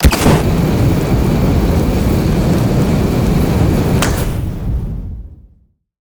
flamethrower-start-2.ogg